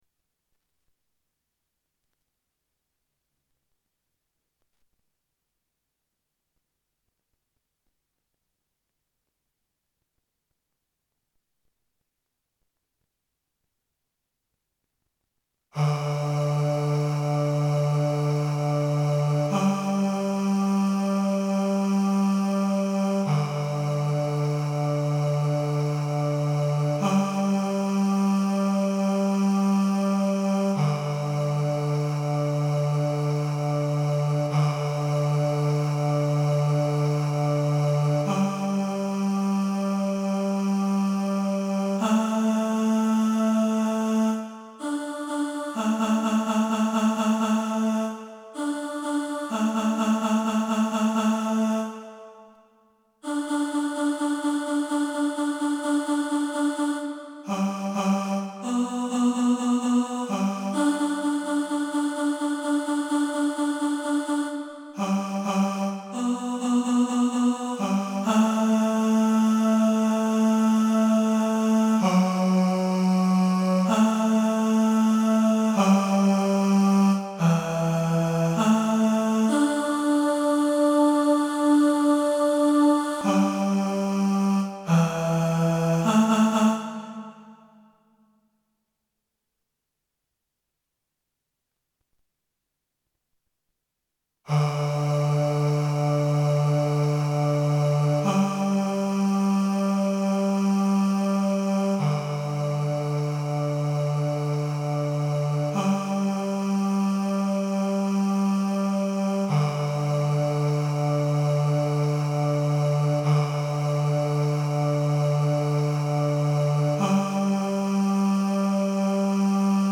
Mamma Mia (Tenor) | Ipswich Hospital Community Choir